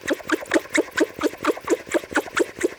cartoon_squeaky_cleaning_loop_04.wav